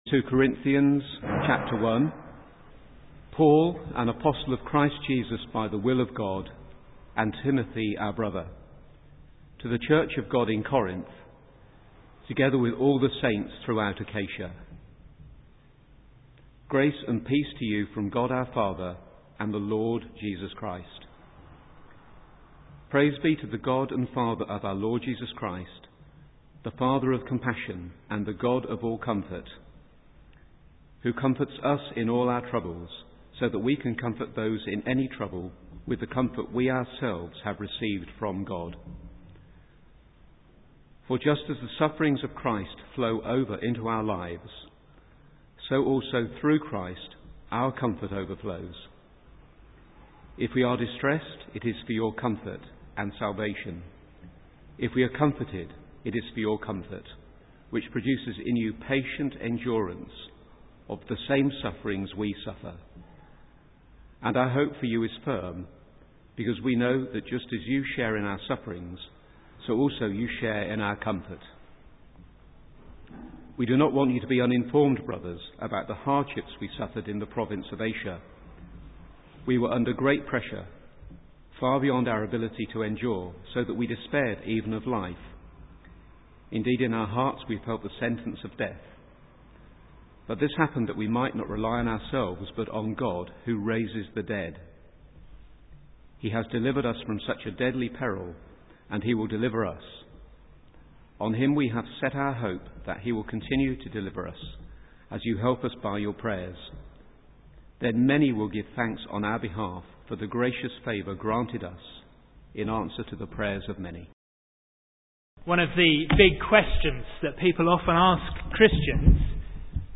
given at a Wednesday meeting